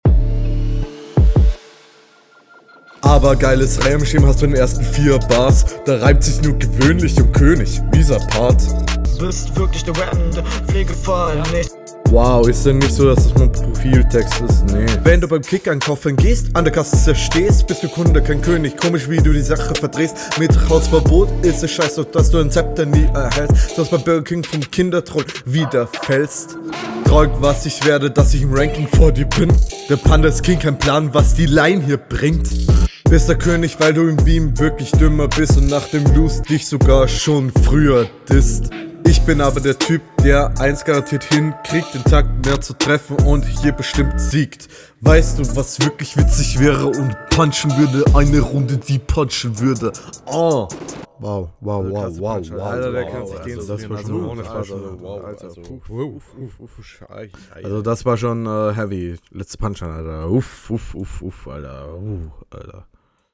ich habe an diesem song nichts auszusetzen sauber produziert text ok flow geschmacksache aber der …